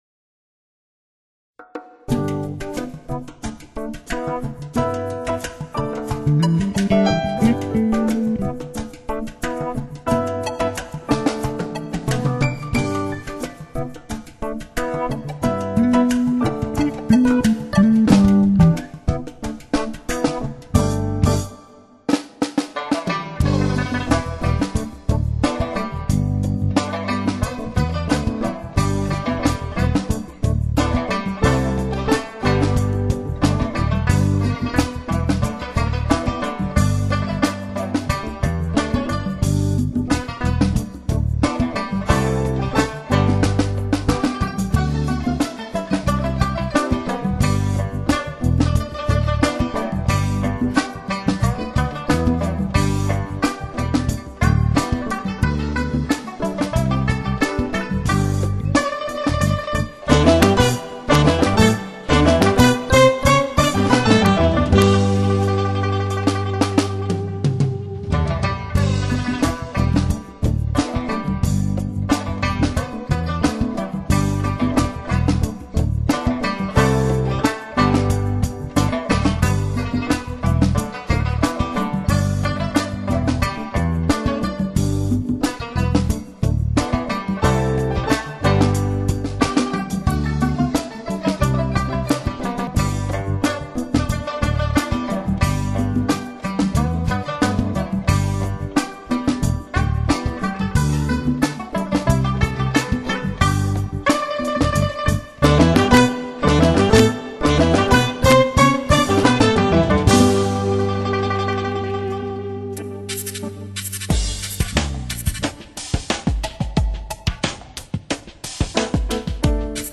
音乐成熟干练，声部清晰明快，配器精致独到，乐手的演奏功底深厚。
CD中12首曲子，总体来说主要体现钢琴的演奏，但风格多样化，从中你会找到你所喜欢的音乐。
这是一张清丽美妙的jazz fusion唱片，浓郁的lounge气息直教人陶醉。